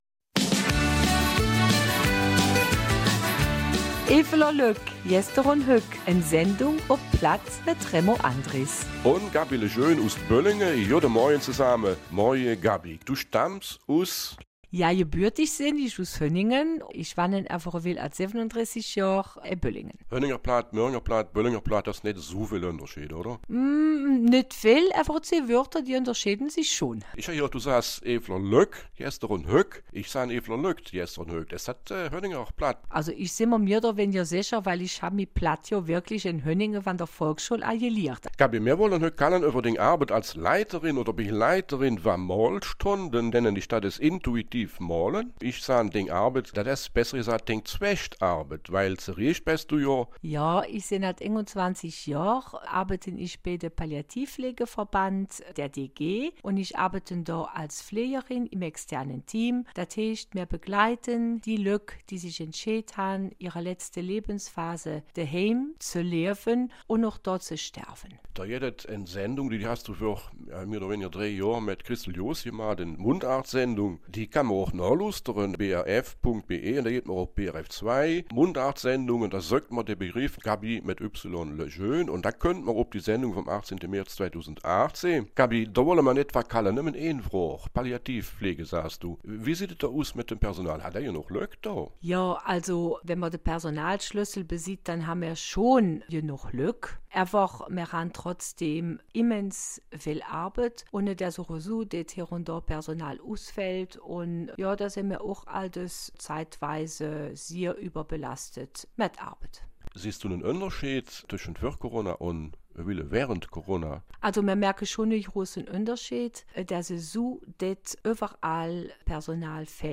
Eifeler Mundart - 12. Dezember